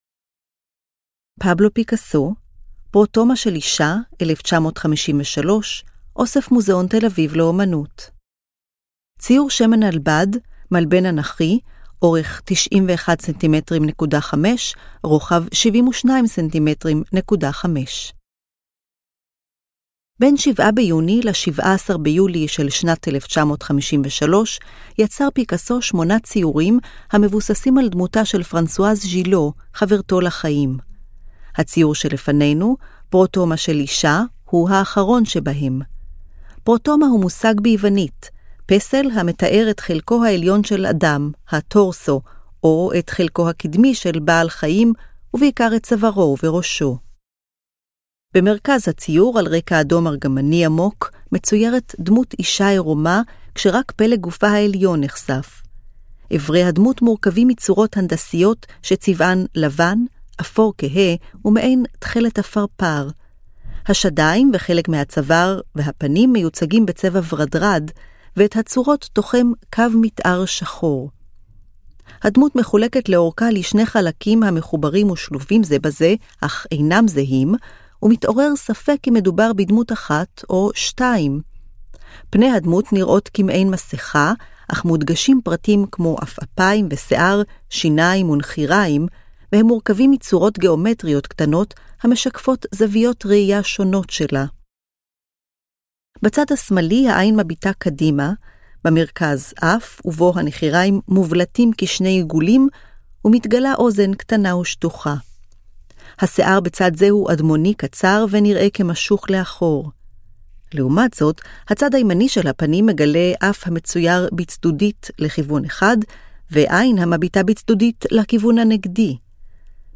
היצירות כולן הונגשו כאמור באמצעות: טקסט – תיאור מורחב המתאר את פרטי היצירה, אודיו – הקלטת התיאור המורחב אותו ניתן לשמוע במדריך הקולי של המוזיאון, גרפיקה טקטילית - הבלטה של היצירות באמצעות מדפסות ברייל, ברייל – תרגום הטקסט המורחב לכתב ברייל.